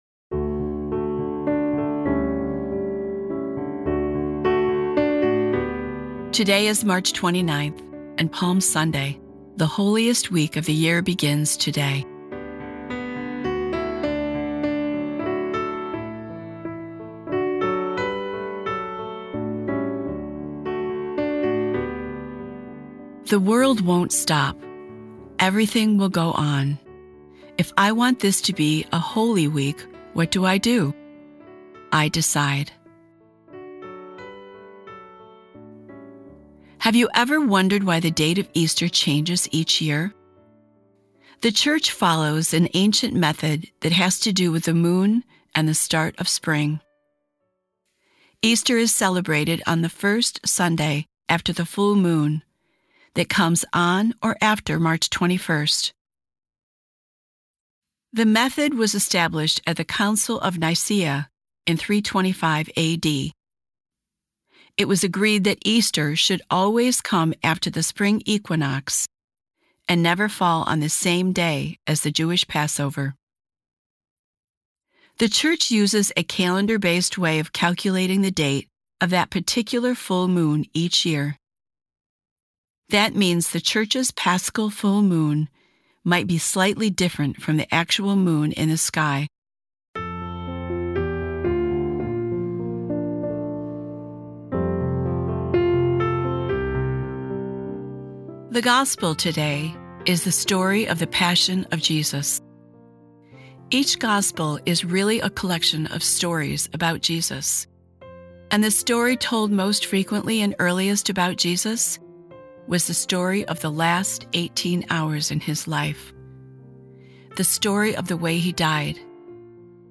Today's episode of Sundays with Bishop Ken is a reading from The Little Black Book: Lent 2026.